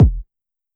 EDMKick_MJ.wav